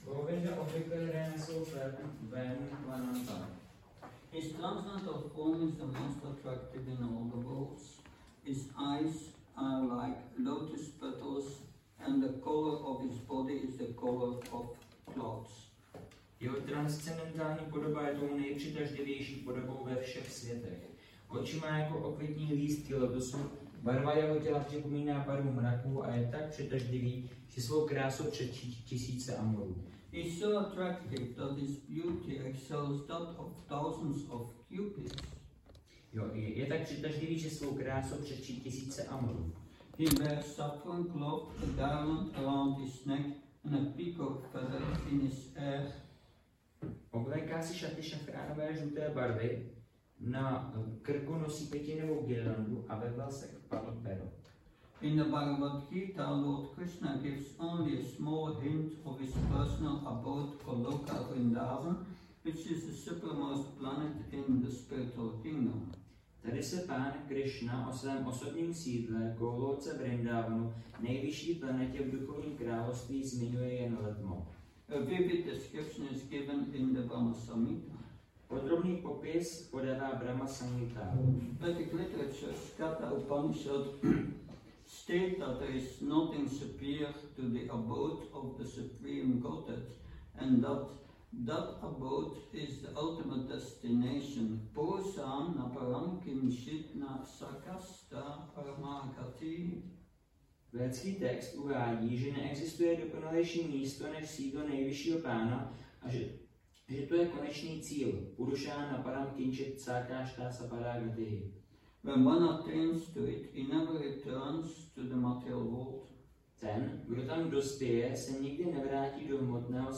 Přednáška BG-8.21- restaurace Góvinda